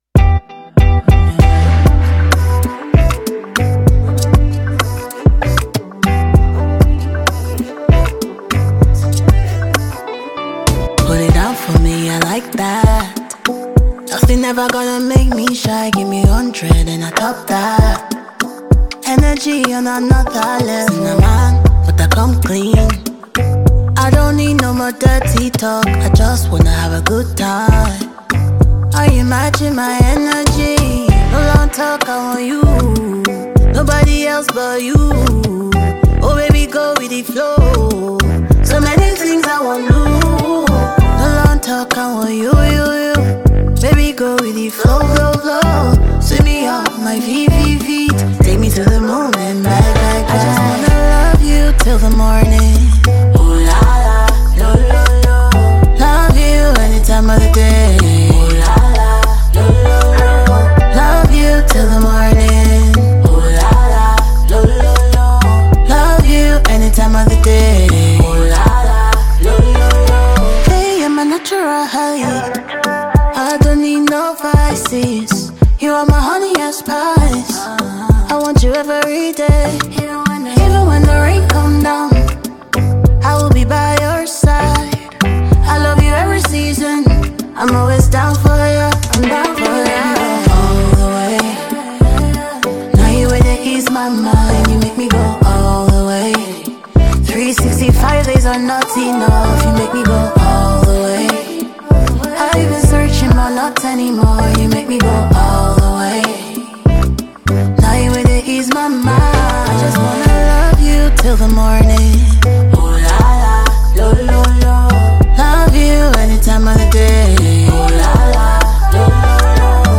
coupled with her soothing vocals